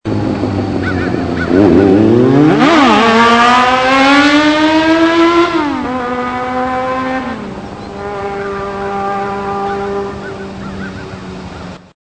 Sound-file Extreme-sound (size = 96 kb )
kawasound.mp3